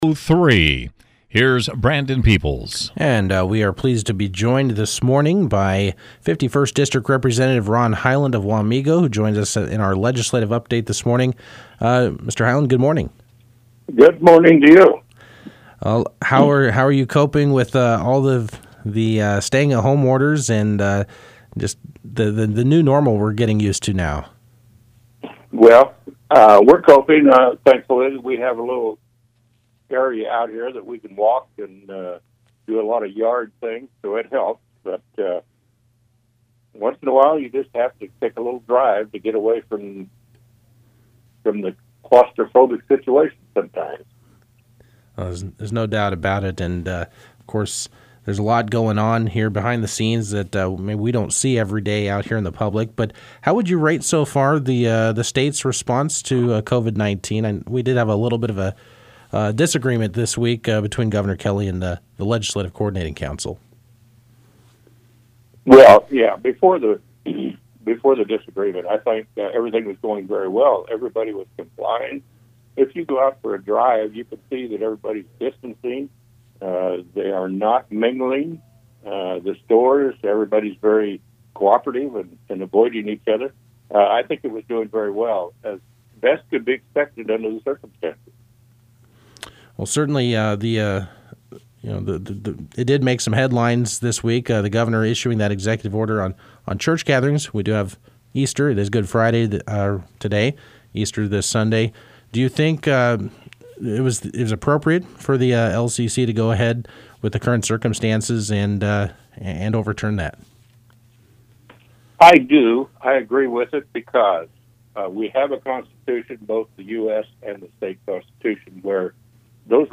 Kansas 51st District Representative Ron Highland of Wamego joined KMAN Friday during the 8 a.m. hour to discuss COVID-19 related matters, the Legislative Coordinating Council’s decision to rescind Gov. Kelly’s executive order on church gatherings and how different state departments are responding.